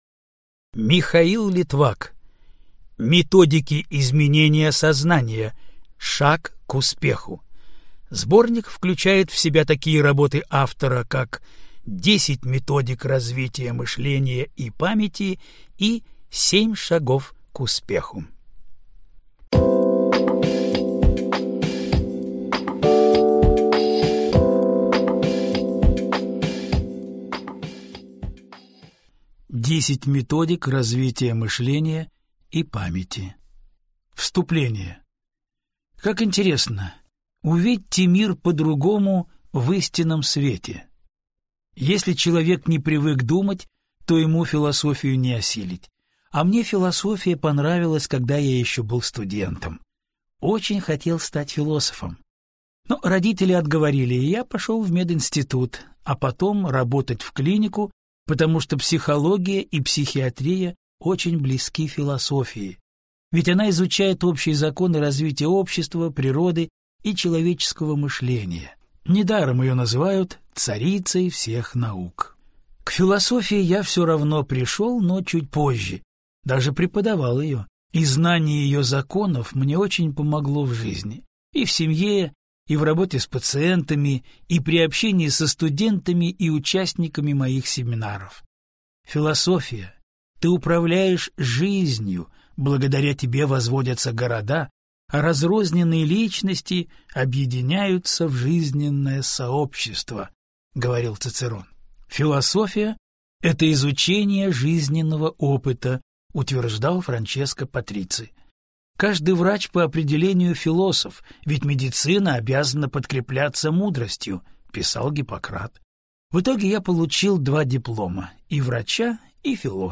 Аудиокнига Методики изменения сознания. Шаг к успеху | Библиотека аудиокниг